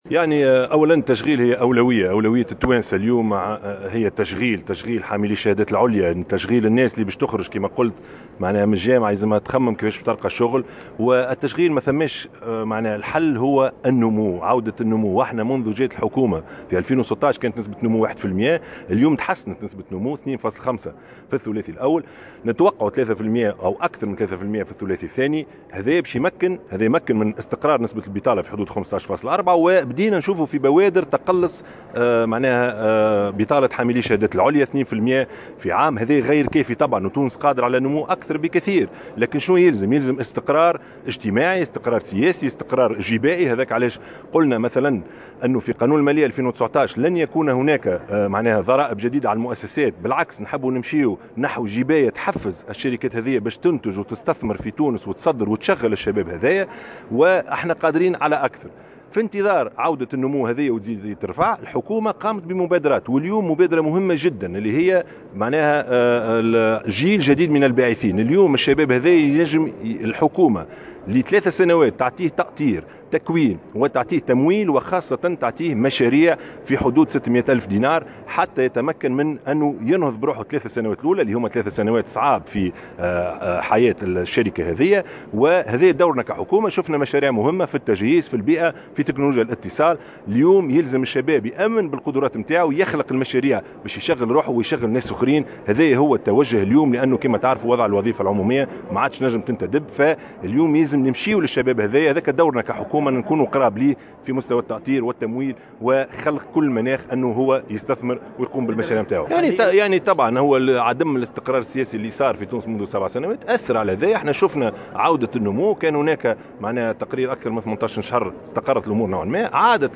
وأكد رئيس الحكومة، في تصريح لمراسلة الجوهرة أف أم، لدى إشرافه، اليوم الأربعاء، على لقاء وطني حول دفع التشغيل، بمدينة العلوم، أن تونس قادرة على تحقيق نسب نمو أكبر بكثير من 2.5 % التي تم تحقيقها في الثلاثي الأول من 2018، وهو ما يتطلب تحقيق استقرار سياسي واجتماعي وجبائي.